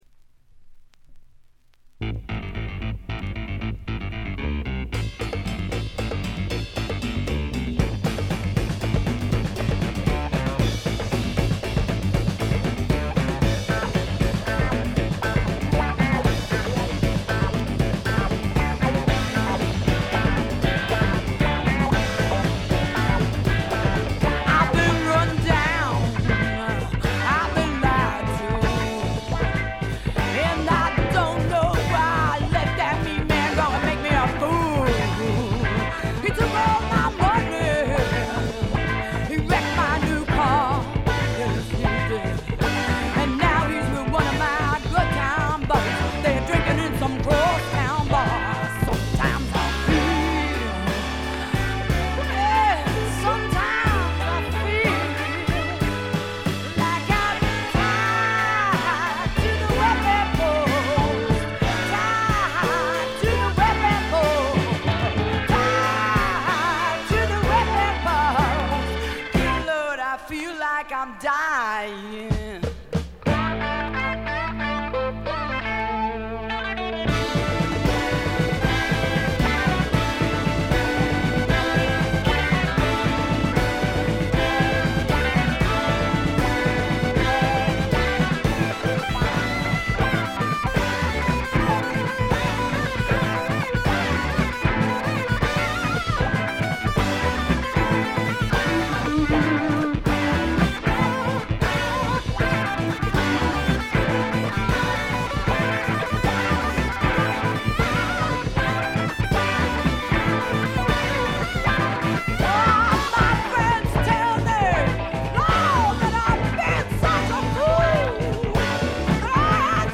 部分試聴ですが、わずかなノイズ感のみ。
ファンキーでタイト、全編でごきげんな演奏を繰り広げます。
試聴曲は現品からの取り込み音源です。
Recorded At - The Sound Factory